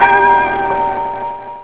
jackpot.au